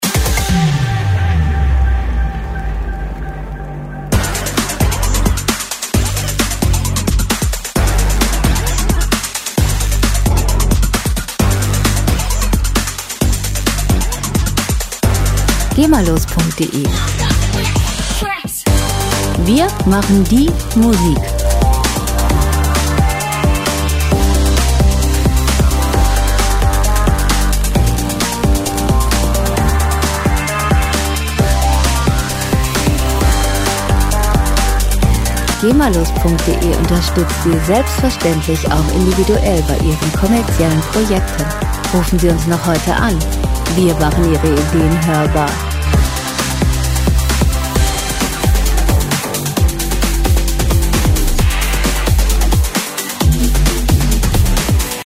• Action-Musik für Extrem-Sportler